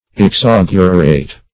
Exaugurate \Ex*au"gu*rate\